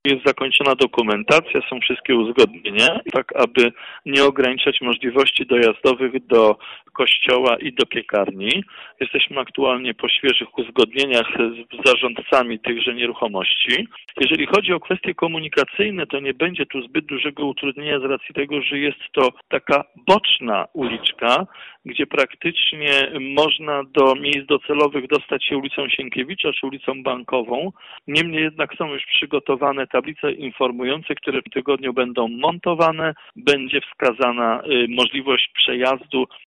Prace odbywać będą się w dwóch etapach, pierwszy to od bloku przy ul. Sienkiewicza, drugi od ul. Sienkiewicza do kanału noteckiego. Remont ul. Farnej nie ma jednak wpłynąć na kwestie komunikacyjne uspokaja burmistrz Jacek Idzi Kaczmarek.